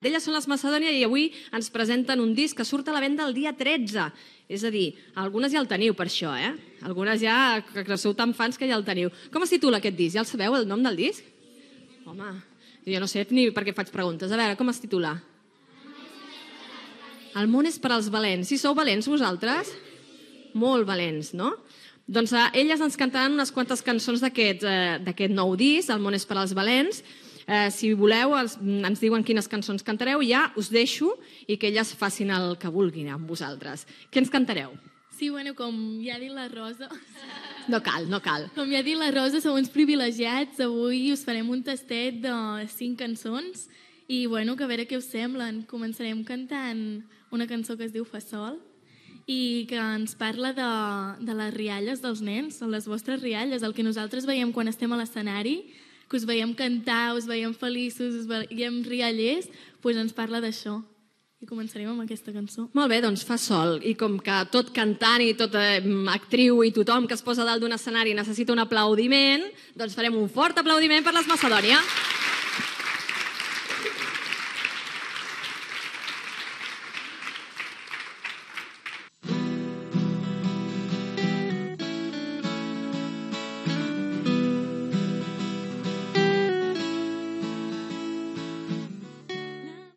Presentació del grup Macedònia que estrena algunes cançons en un programa especial fet al Museu de la Xocolata de Barcelona
Infantil-juvenil